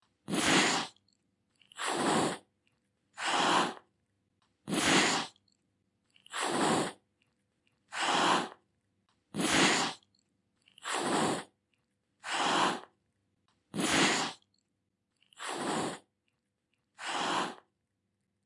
Tiếng con Mèo gầm, rít… tức giận
Thể loại: Tiếng vật nuôi
Description: Tiếng mèo gầm gừ, rít lên đầy tức giận thể hiện sự hung hăng, khó chịu. Âm thanh khàn khàn, sắc bén như tiếng rống, rú dữ dội khi bị đe dọa. Tiếng kêu gằn gằn, kèn kèn, gào thét đầy căng thẳng. Mèo phát ra âm thanh sậm sùi, gừ gừ từ cổ họng, tạo hiệu ứng đáng sợ. Tiếng hú, tru, kêu quằn quại thể hiện cảm xúc bùng nổ...
tieng-con-meo-gam-rit-tuc-gian-www_tiengdong_com.mp3